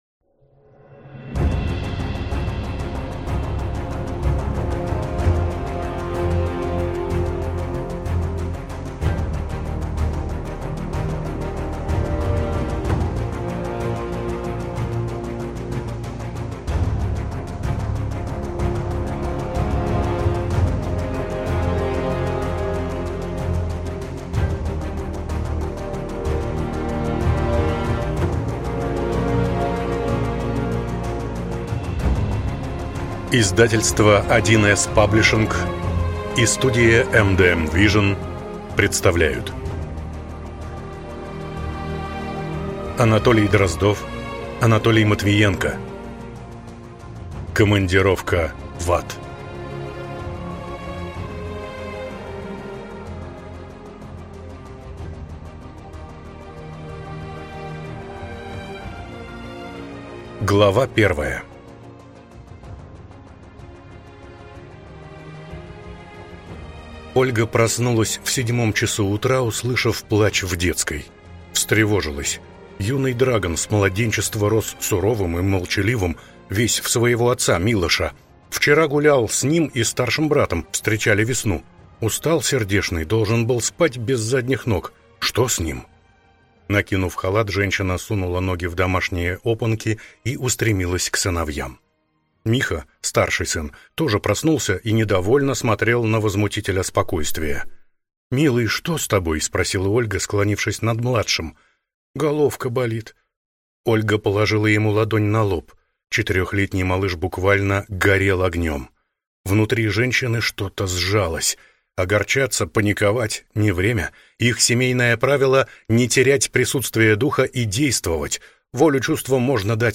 Лорд блуждающих земель – 2 (слушать аудиокнигу бесплатно) - автор Виктор Молотов